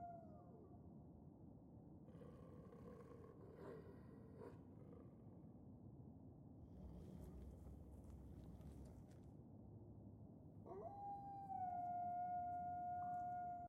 sfx_amb_map_settlement_enemycamp.ogg